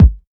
pcp_kick04.wav